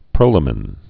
(prōlə-mĭn) also pro·la·mine (-mĭn, -mēn)